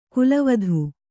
♪ kulavadhu